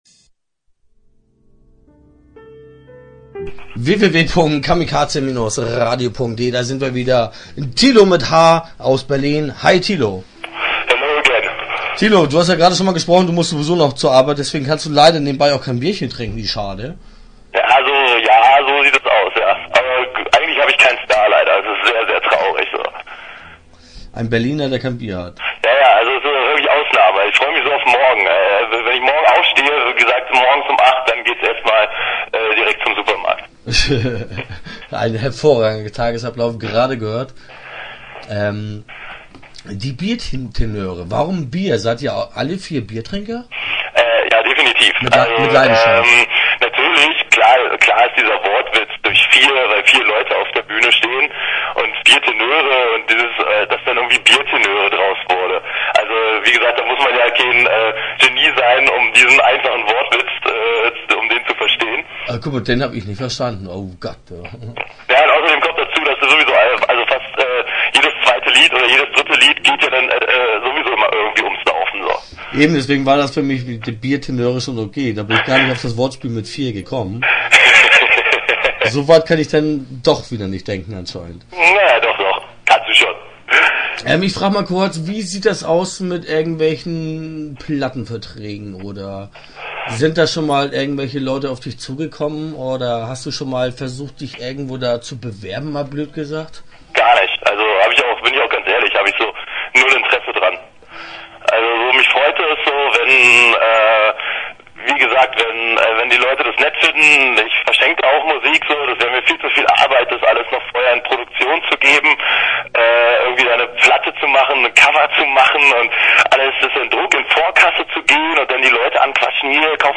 Kamikaze Radio spricht am Telefon
Interview Teil 1 (11:30)